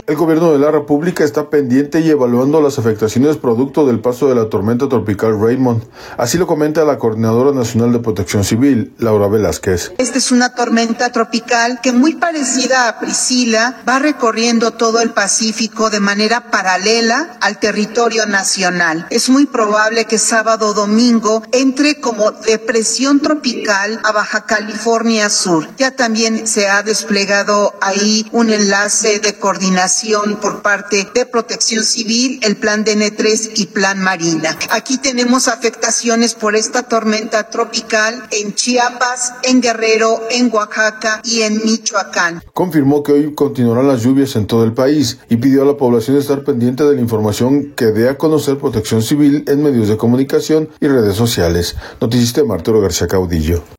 El Gobierno de la República está pendiente y evaluando las afectaciones producto del paso de la tormenta tropical Raymond, así lo comenta la coordinadora nacional de Protección Civil, Laura Velázquez.